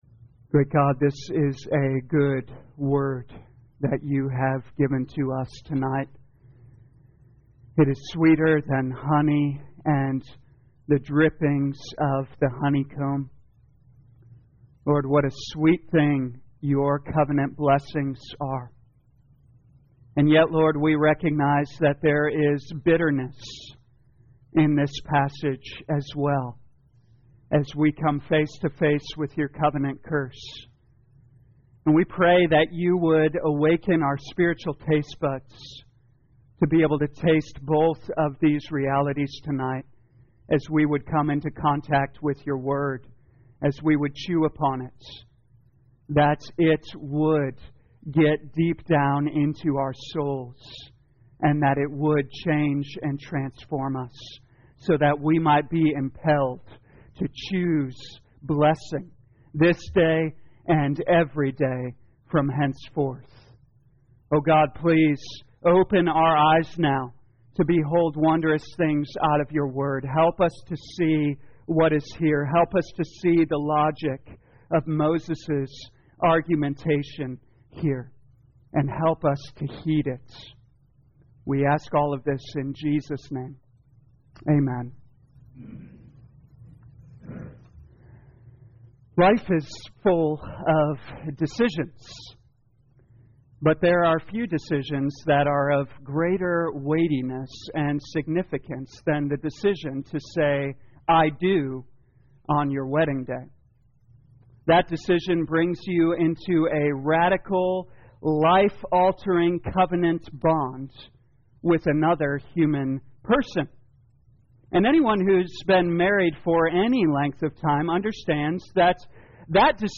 2022 Deuteronomy The Law Evening Service Download